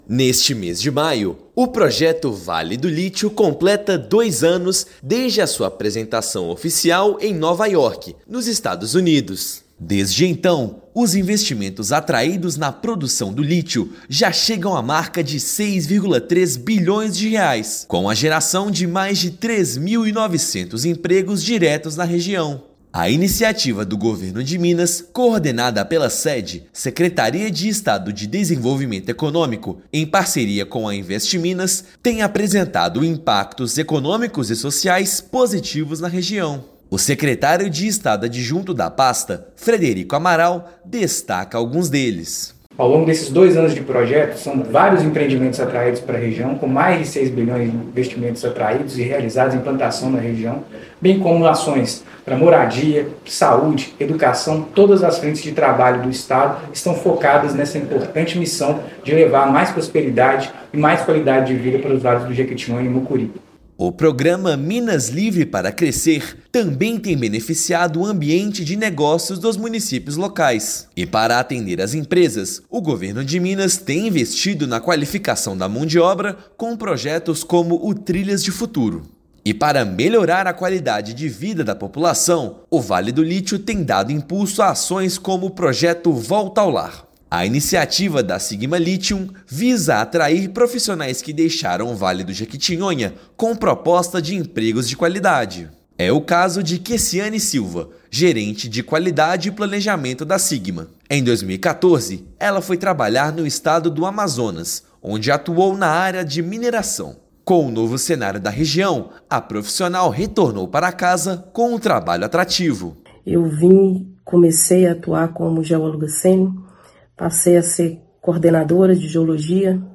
[RÁDIO] Vale do Lítio: projeto estratégico do Governo de Minas celebra dois anos transformando vidas na região
Ações conjuntas do Estado geraram desenvolvimento econômico e qualidade de vida para a população nos vales do Jequitinhonha, Mucuri e Norte de Minas. Ouça matéria de rádio.